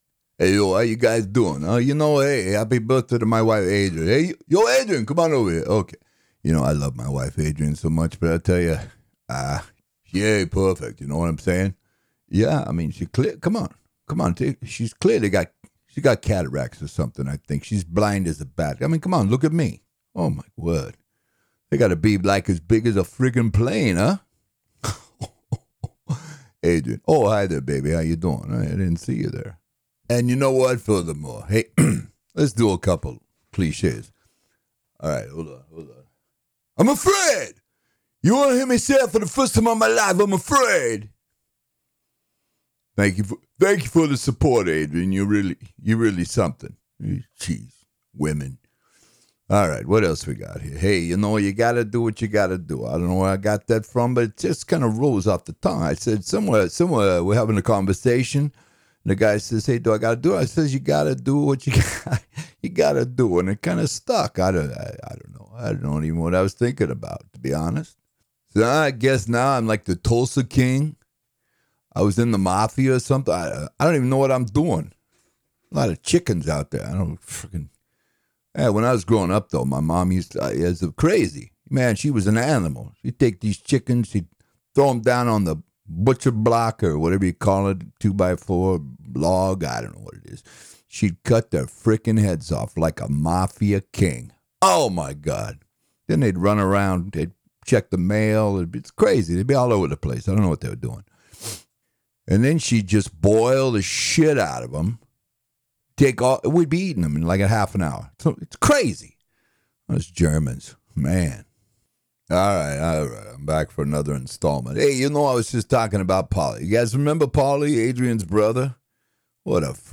Male
Adult (30-50), Older Sound (50+)
Deep diverse, specializing in Characters from Sly Stallone to British Royalty
Smooth, articulate, funny, Radio Announcer, Movie Trailer, instructor
Character / Cartoon